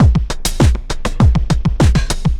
pulse tombeat 100bpm 03.wav